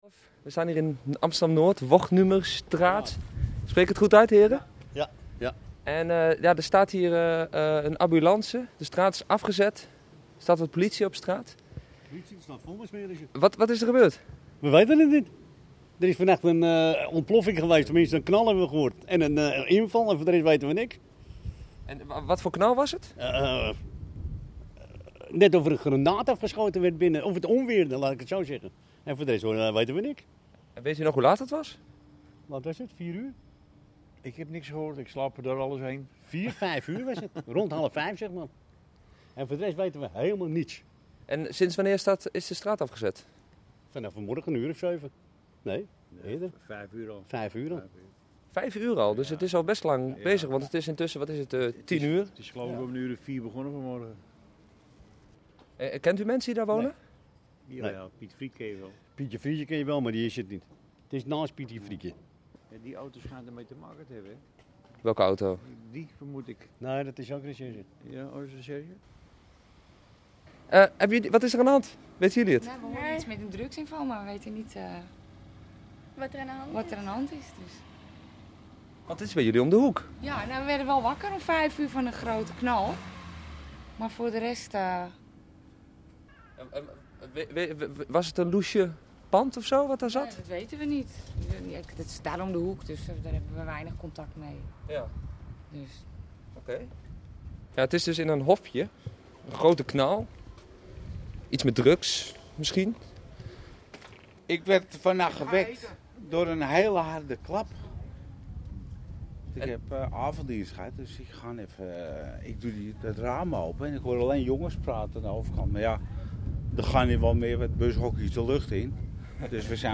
Een rustige buurt in Amsterdam Noord werd vanochtend wakker geschud door een harde knal en een grootschalige politieoperatie. Een reactie van de buurtbewoners.